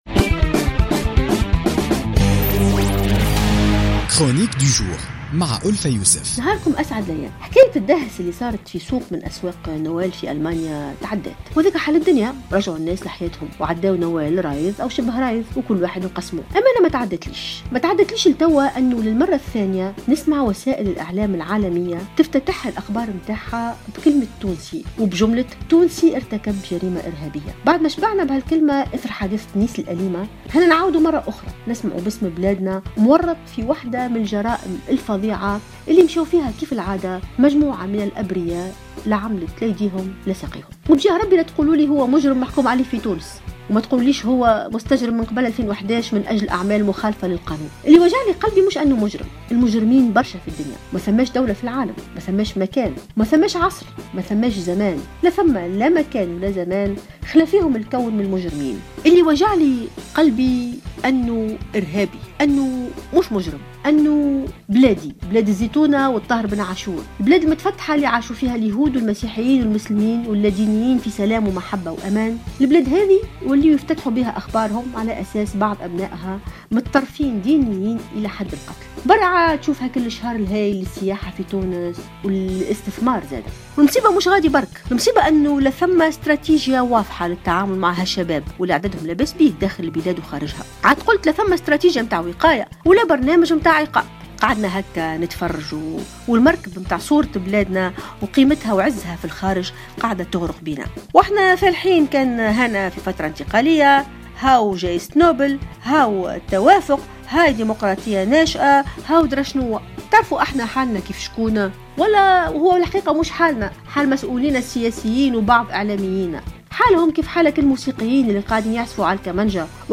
وقالت في افتتاحيتها لـ "الجوهرة اف أم" إنه لا توجد أي وقاية من مثل هذه الممارسات الارهابية كما لا يوجد أي عقاب لمرتكبي مثل هذه الجرائم.